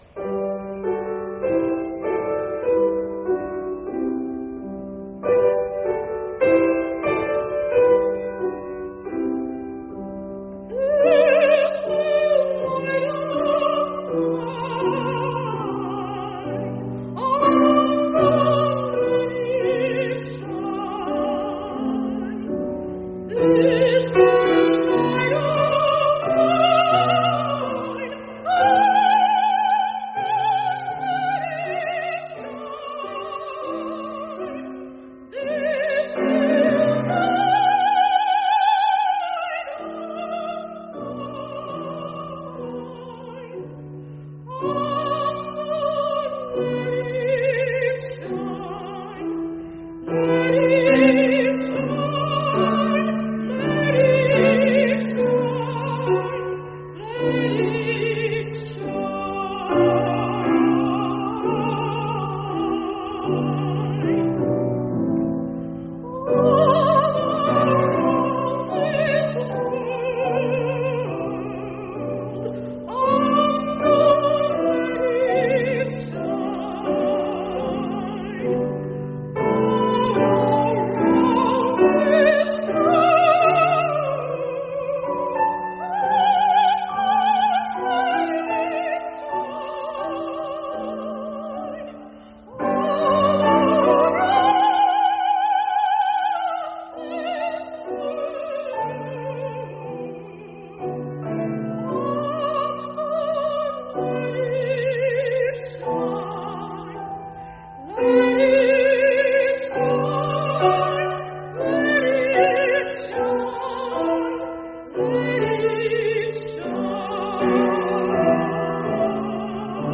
Music: Solo Recital
Instrument or voice part in the sample: soprano